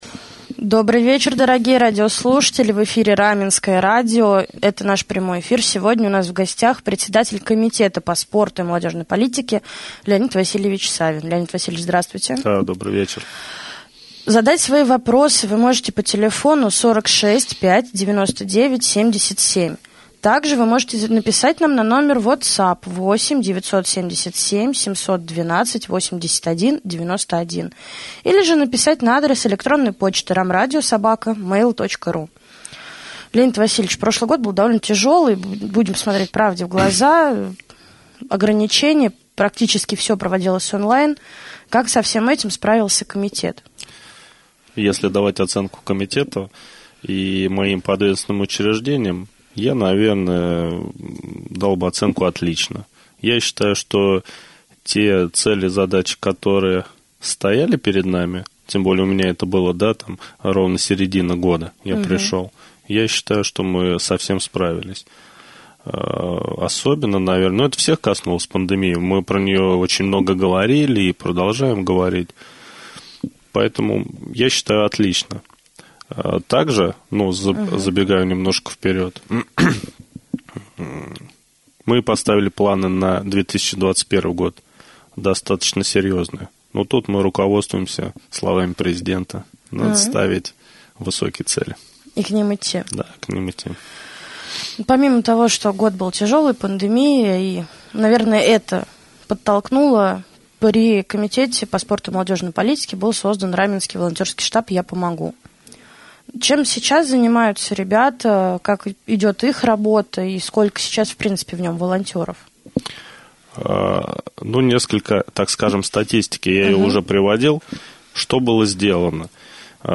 В гостях у Раменского радио побывал председатель Комитета по спорту и молодежной политике Леонид Васильевич Савин.